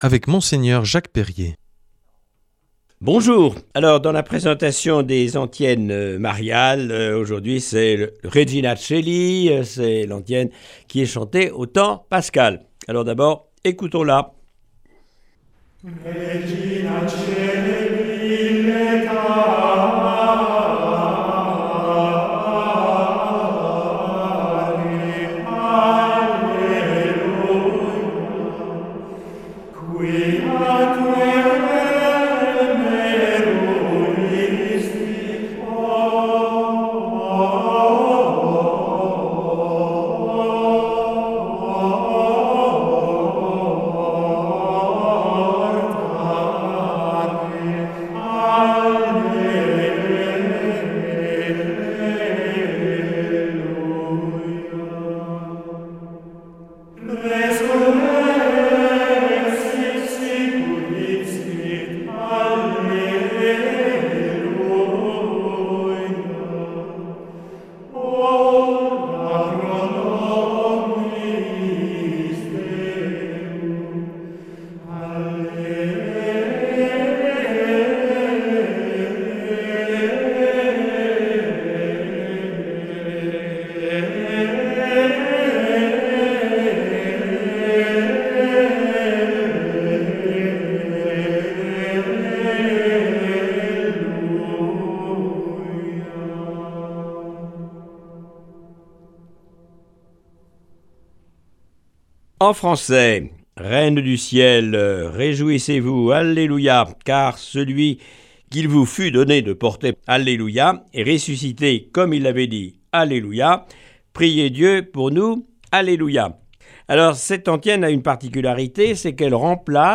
Cette semaine, Mgr Jacques Perrier nous propose des méditations sur des antiennes mariales. Aujourd’hui : Régina Caeli.